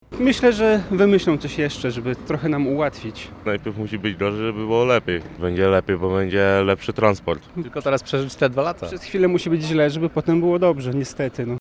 Jak wskazał burmistrz Kościerzyny Dawid Jereczek, zdaniem mieszkańców rozkład wymaga korekty.